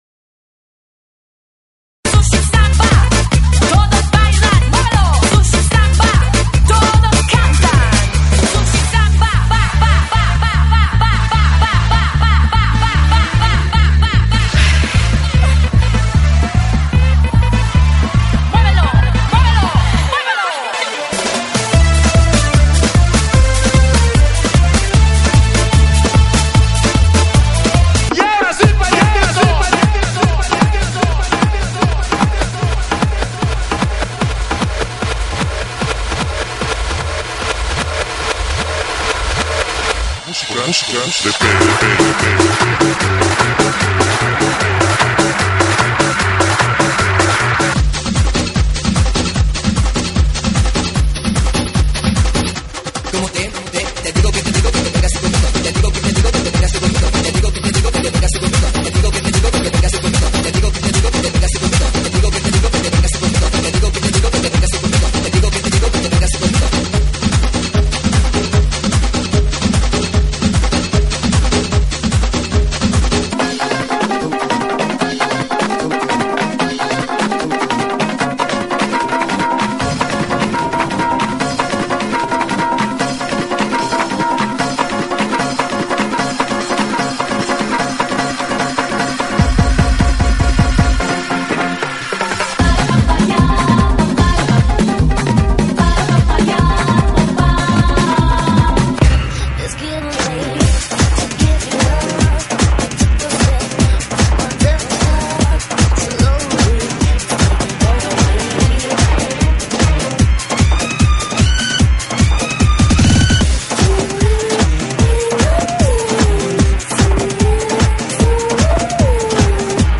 GENERO: LATINO